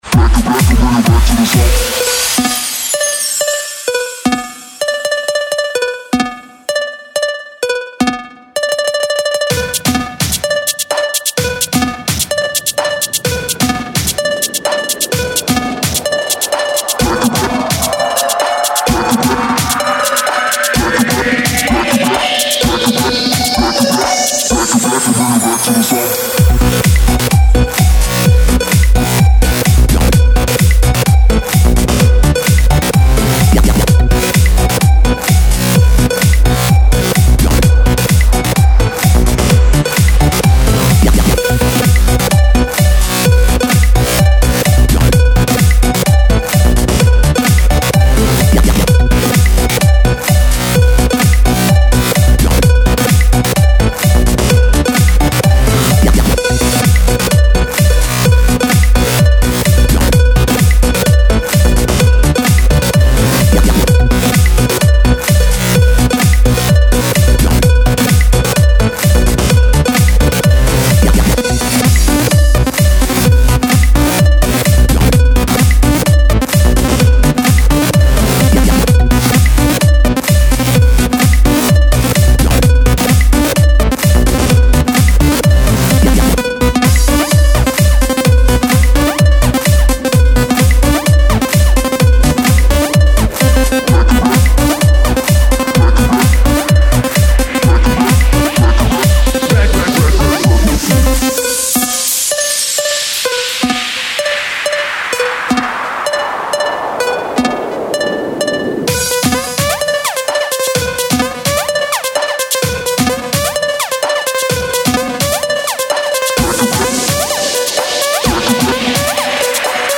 Жанр: Сlub